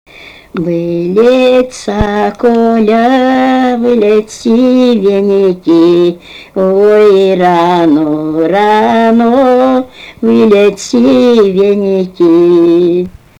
daina
Aukštadvaris
vokalinis